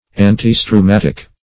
Search Result for " antistrumatic" : The Collaborative International Dictionary of English v.0.48: Antistrumatic \An`ti*stru"mat"ic\, a. (Med.)